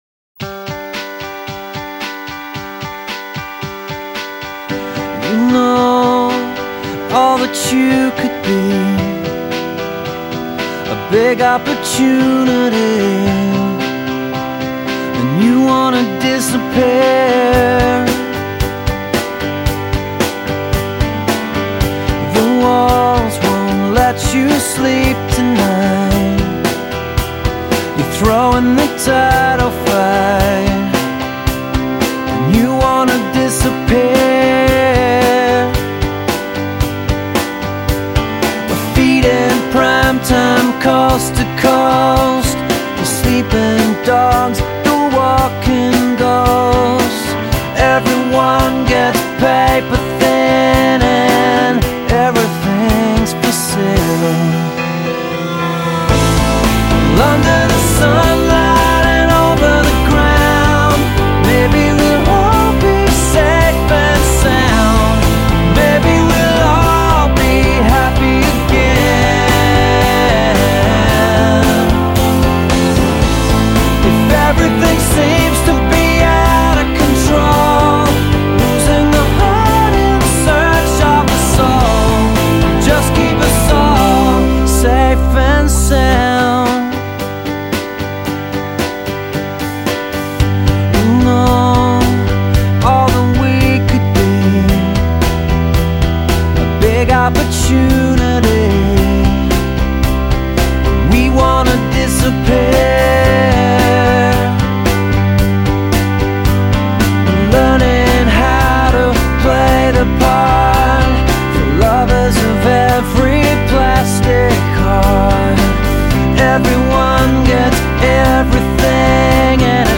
Australian-born singer-songwriter